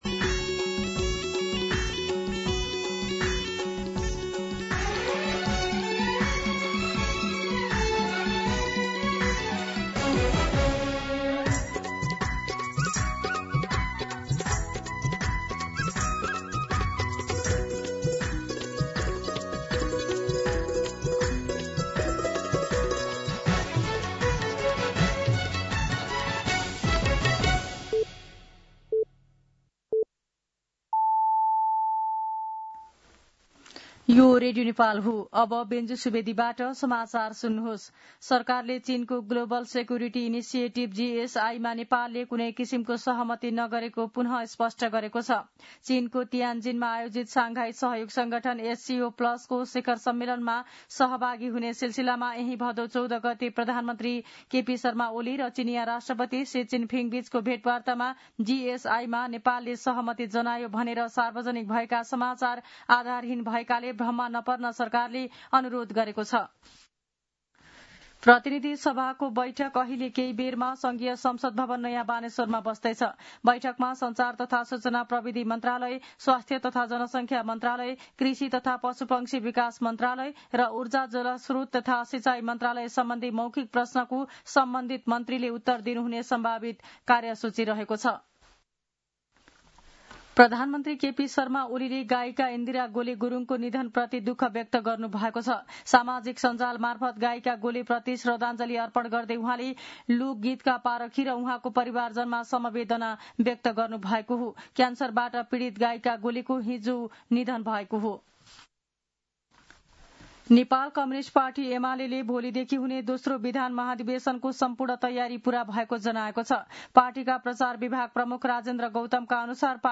दिउँसो १ बजेको नेपाली समाचार : १९ भदौ , २०८२